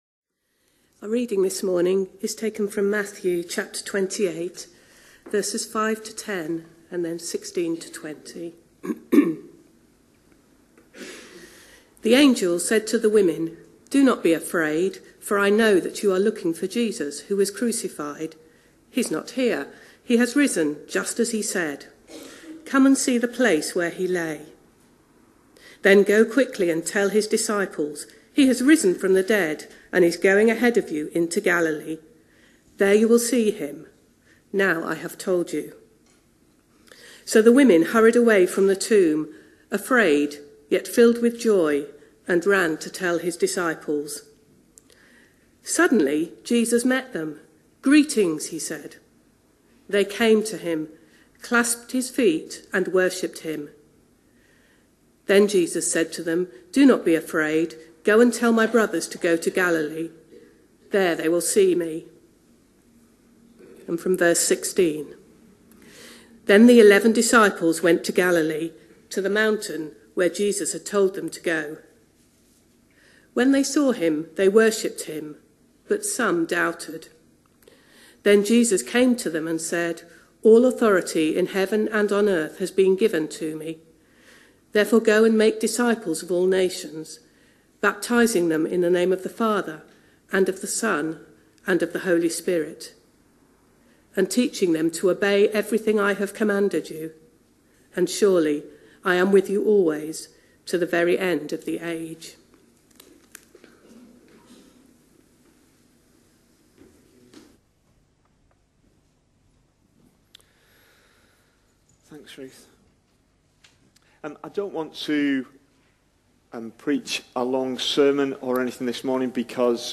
A sermon preached on 5th May, 2019.